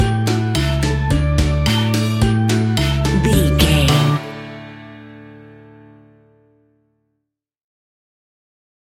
Ionian/Major
D
Fast
kids instrumentals
childlike
cute
kids piano